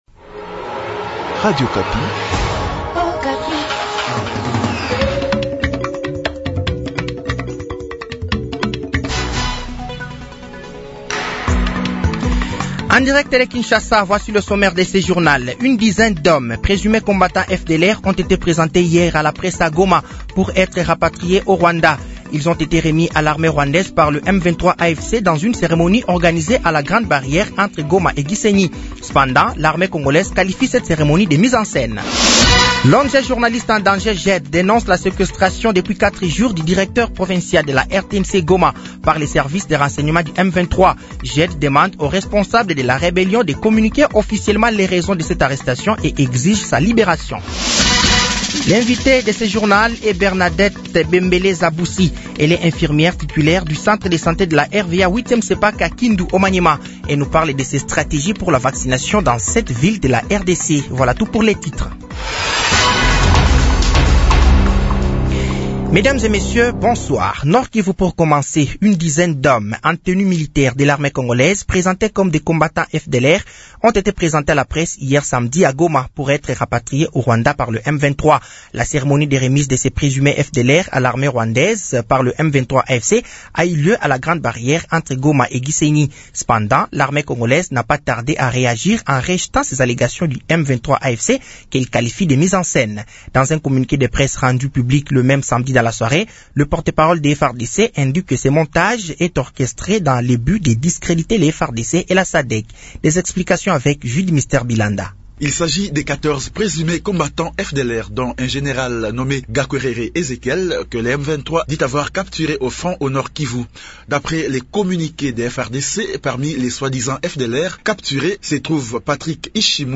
Journal français de 18h de ce dimanche 02 mars 2025